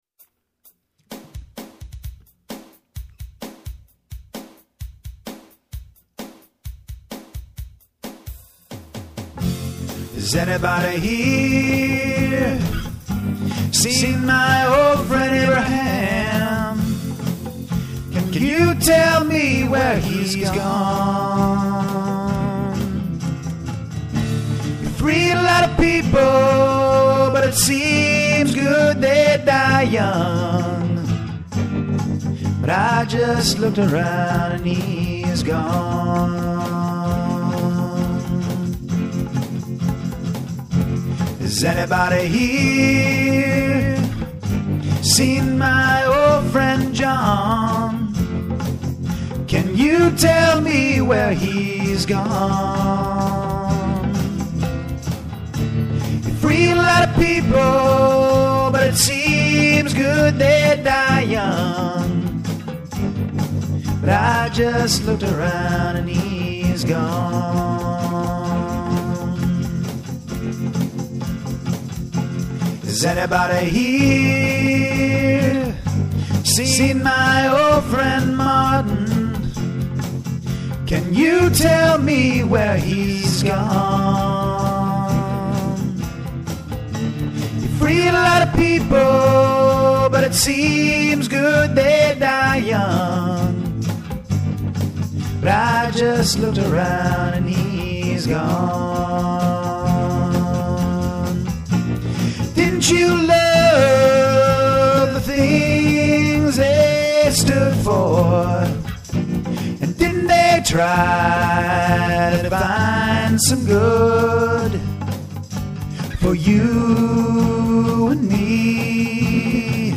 three-piece folk band